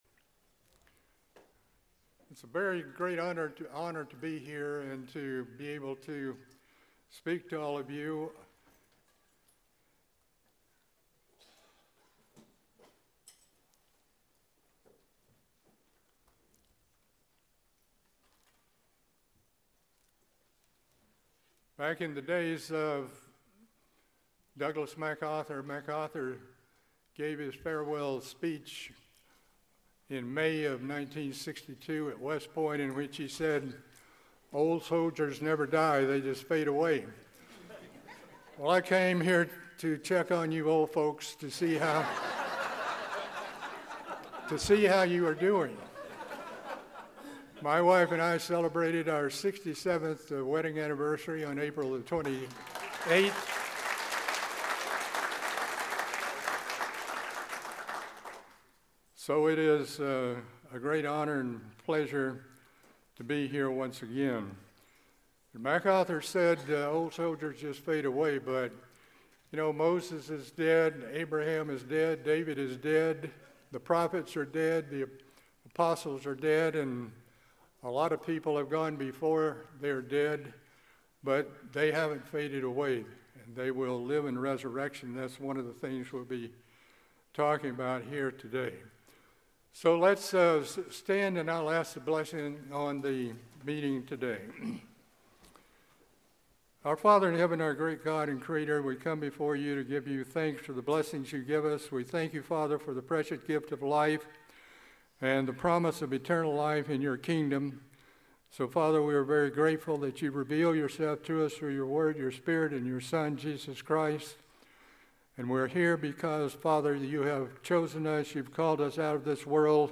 This Bible study, given during the 2023 General Conference of Elders, will identify some of the major doctrinal teachings that distinguish the Church of God from the religious world and the necessity for preaching and teaching those doctrines. The study will explain the plan and purpose of God in bringing sons and daughters to glory in the family of God.